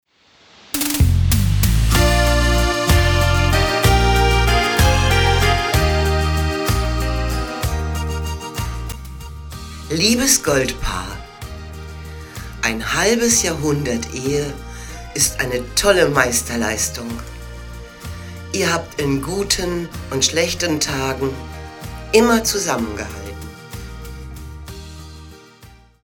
Hörprobe aus dem Gratulationstext
🎶 Geschenk zum 50. Hochzeitstag mit Musik & gesprochenem Gratulationstext